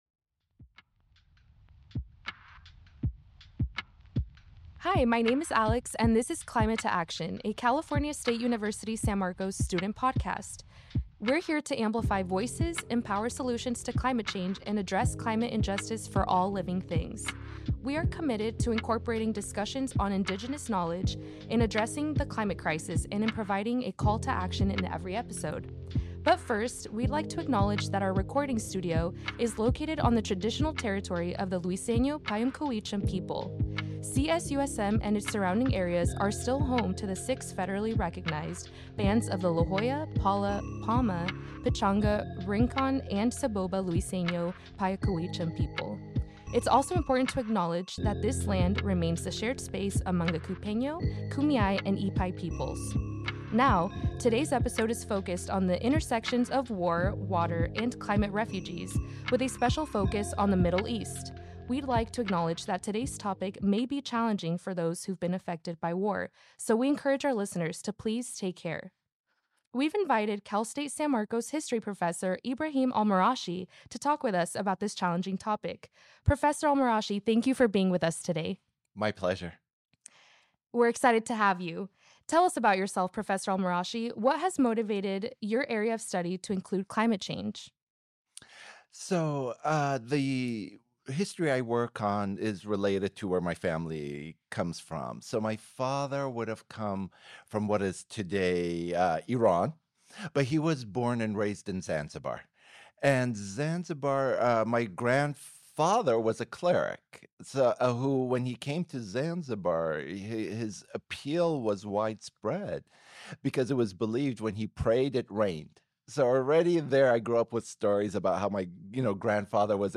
Climate To Action is produced at the CSUSM Inspiration Studios.